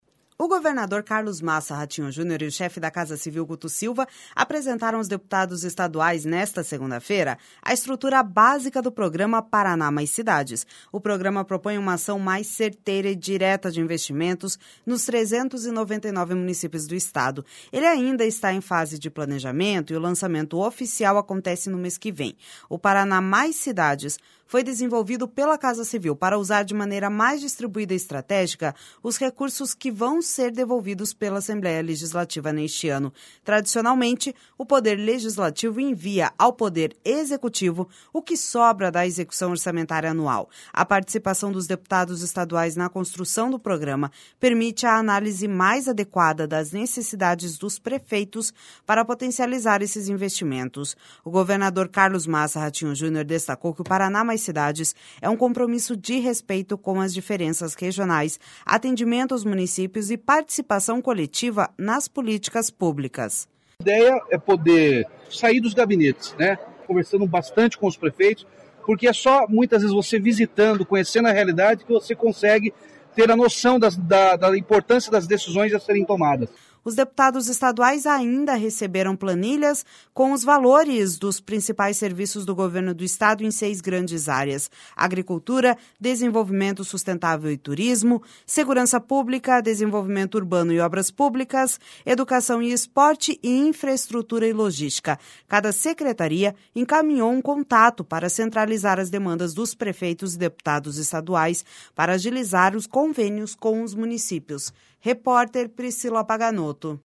O governador Carlos Massa Ratinho Junior destacou que o Paraná Mais Cidades é um compromisso de respeito com as diferenças regionais, atendimento aos municípios e participação coletiva nas políticas públicas.// SONORA CARLOS MASSA RATINHO JUNIOR//Os deputados estaduais ainda receberam planilhas com os valores dos principais serviços do Governo do Estado em seis grandes áreas: Agricultura, Desenvolvimento Sustentável e Turismo, Segurança Pública, Desenvolvimento Urbano e Obras Públicas, Educação e Esporte, e Infraestrutura e Logística.